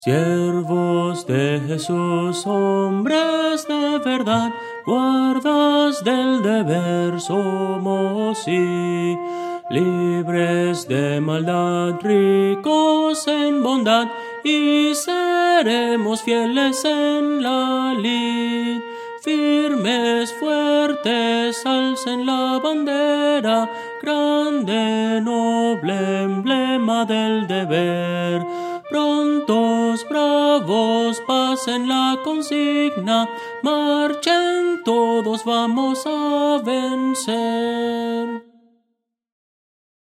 Voces para coro
Soprano – Descargar